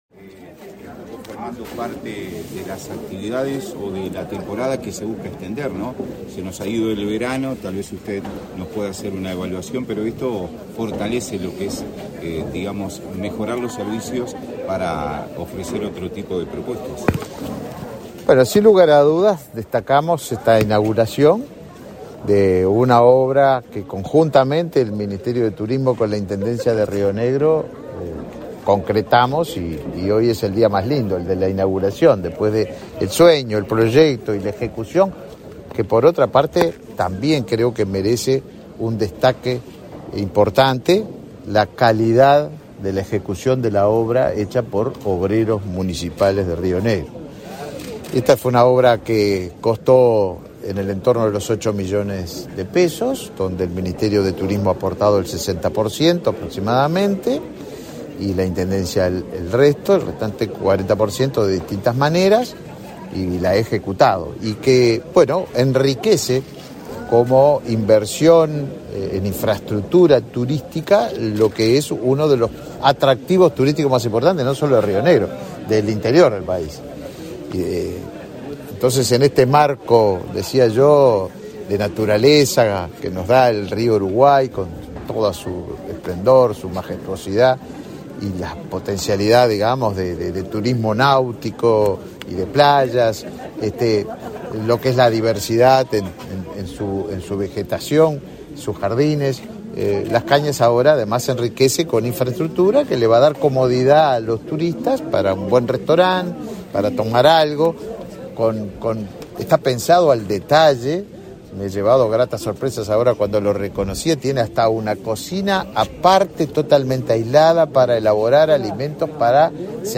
Declaraciones a la prensa del ministro de Turismo, Tabaré Viera
Tras participar en la inauguración de obras en el balneario Las Cañas, este 19 de mayo, el ministro Tabaré Viera, en declaraciones a la prensa,